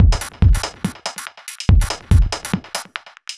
Index of /musicradar/uk-garage-samples/142bpm Lines n Loops/Beats
GA_BeatAFilter142-09.wav